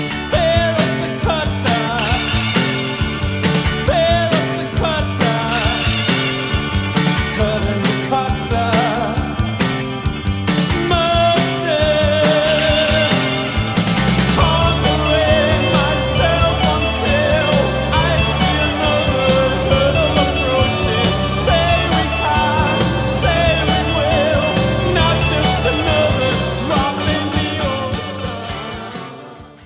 One-second fade-out at the end of the clip.